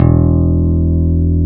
Index of /90_sSampleCDs/Roland - Rhythm Section/BS _Rock Bass/BS _Stretch Bass